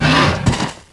Heroes3_-_Azure_Dragon_-_DeathSound.ogg